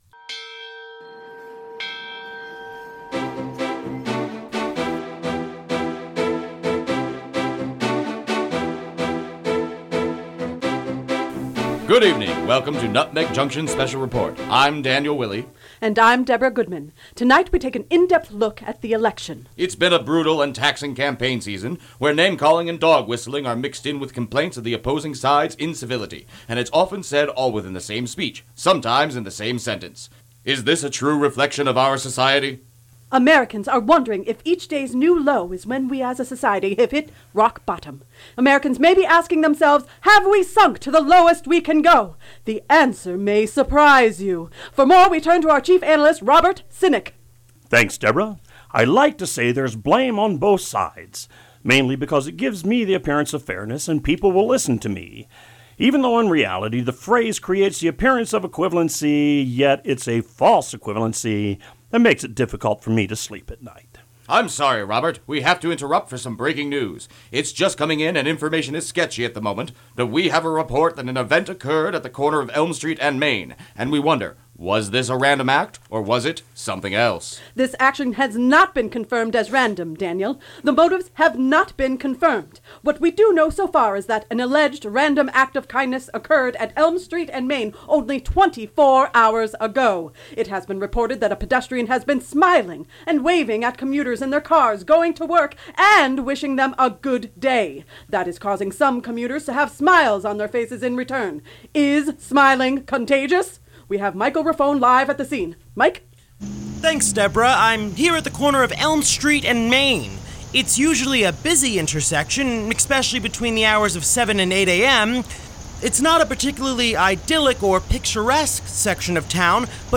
For inspiration I shared with them the Monty Python election coverage segment from a youtube to show the sense of “urgency” they needed to convey. This was a show that had a lot of roles and was one of the largest cast for any single episode and even so we had some actors take on dual roles.